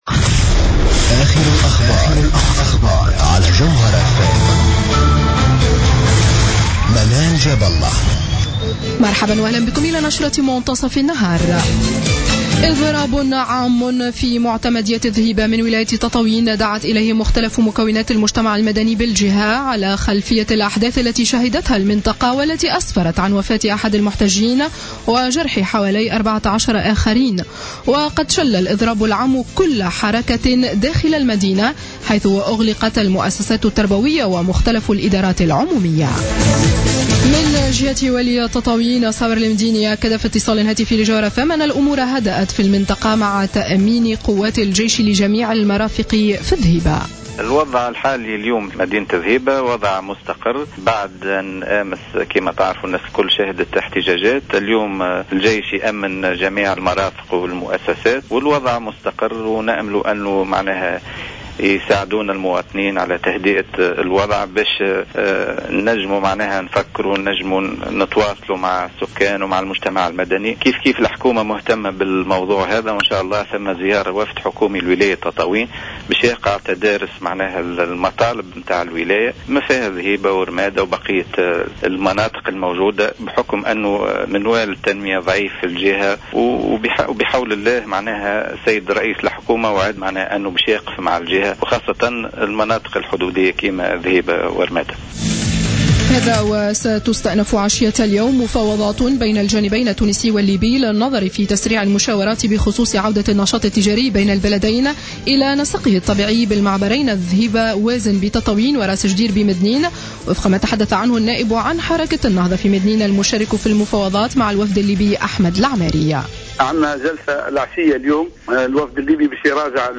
نشرة أخبار منتصف النهار ليوم الاثنين 09-02-15